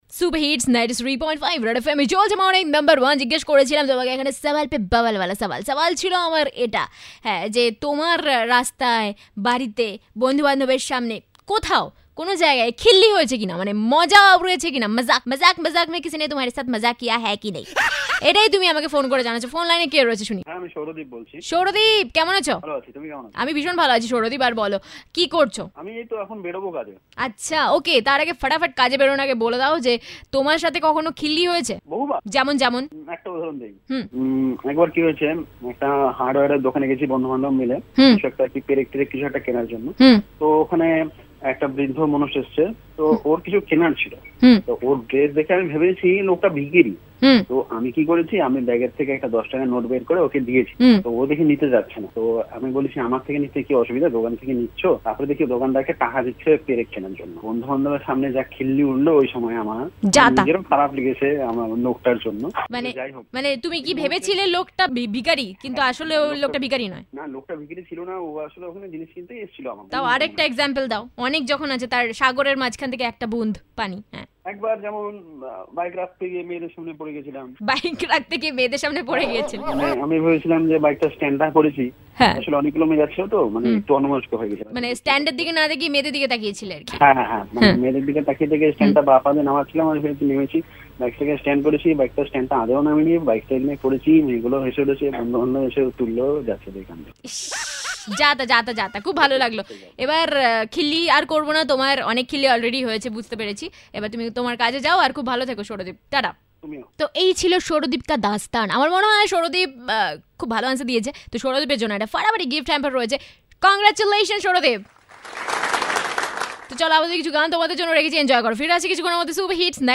caller interaction on topic